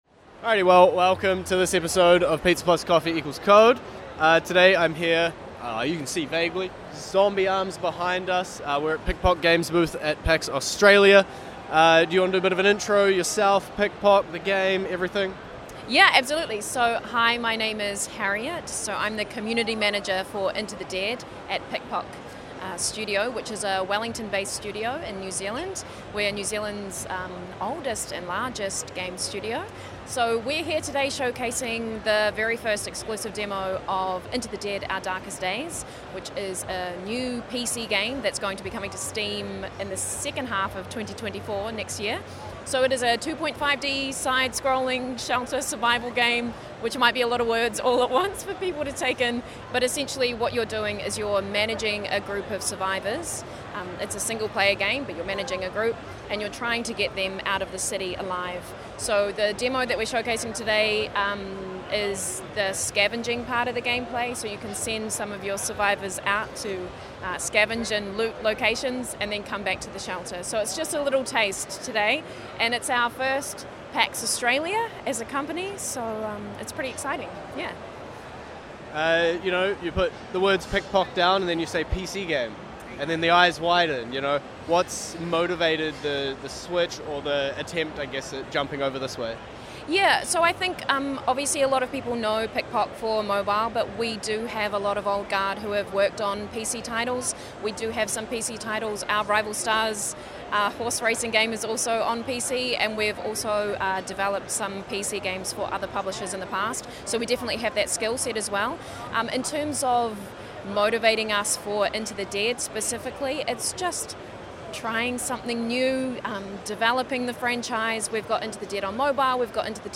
PikPok Games discusses the ambitious new survival game Into the Dead: Our Darkest Days in this exclusive interview.